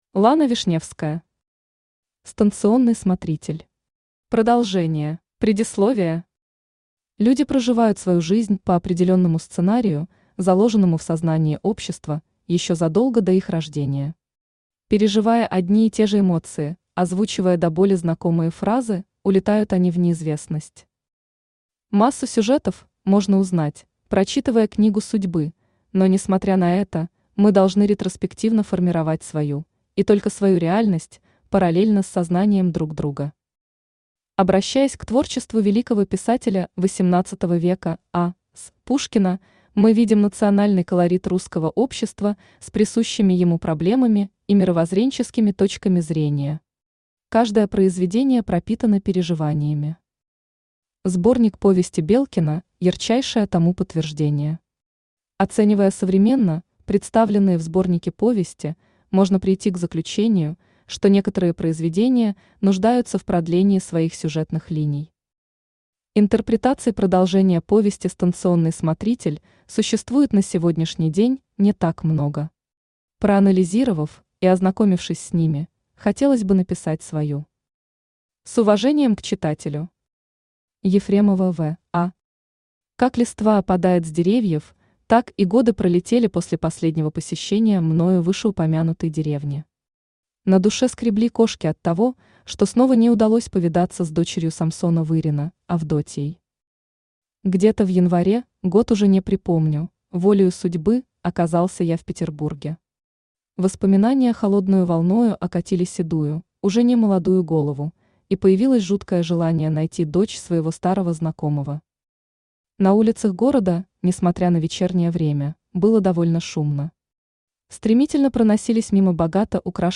Аудиокнига Станционный смотритель. Продолжение | Библиотека аудиокниг
Читает аудиокнигу Авточтец ЛитРес